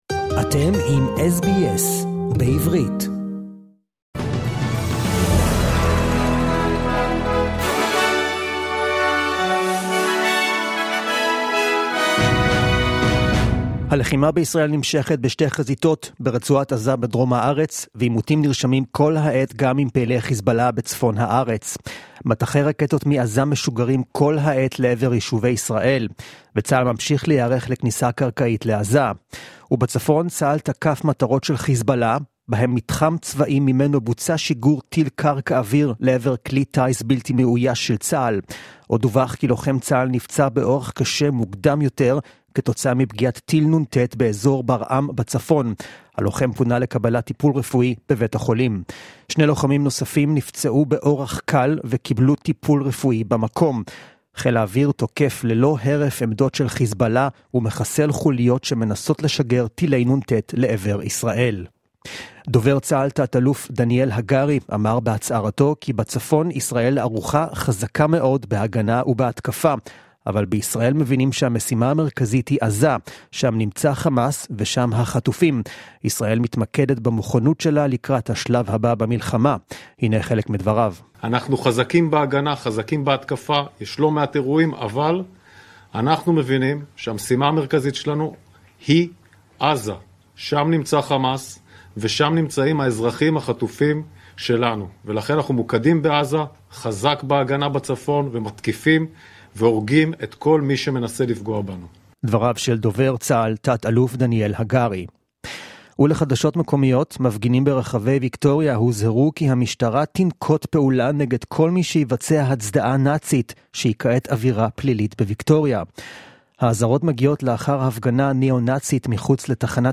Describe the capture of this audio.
The latest news from Israel in Hebrew, as heard on the SBS Hebrew program on Sunday 22nd of October 2023